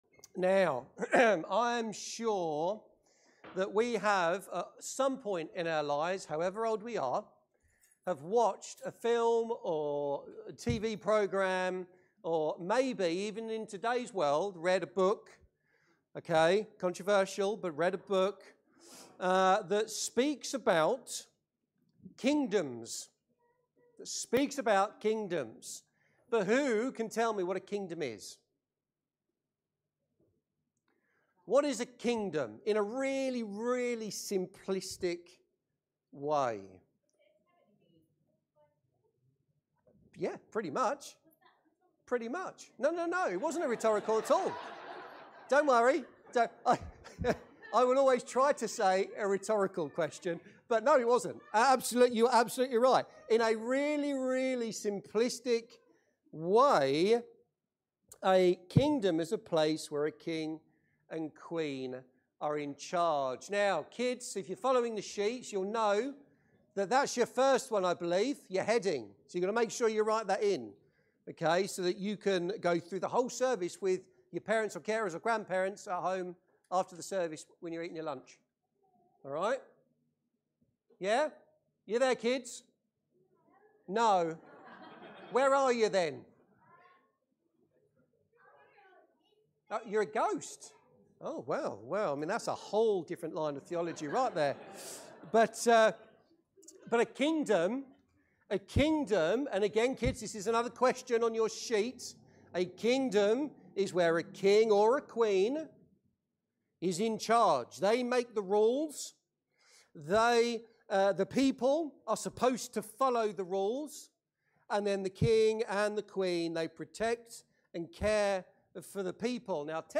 Sermons and Talks - Welcome Church